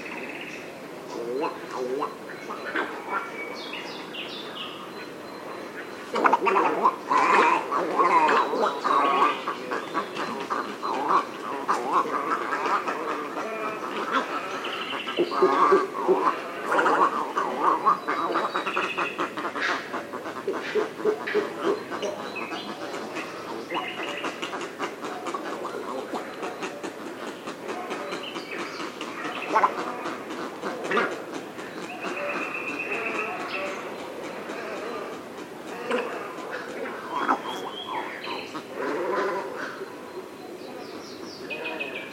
• little egret gargling and chuckling.wav
little_egret_gargling_and_chuckling_552.wav